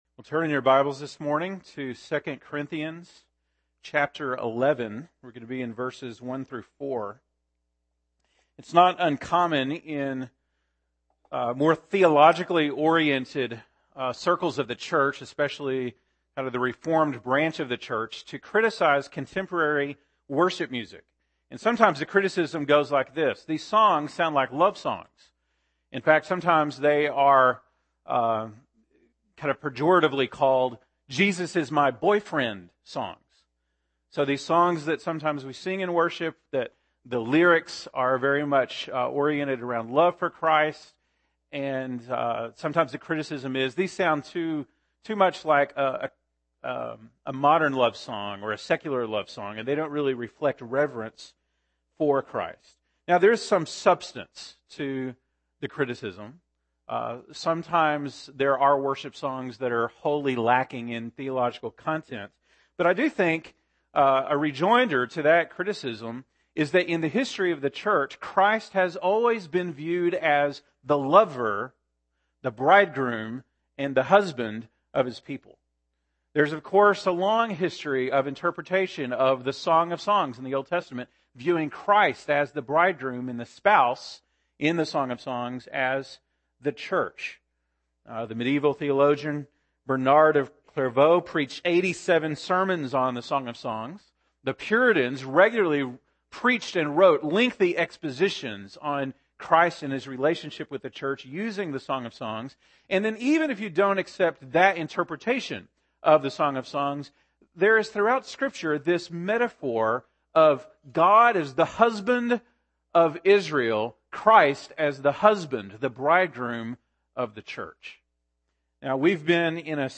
January 3, 2016 (Sunday Morning)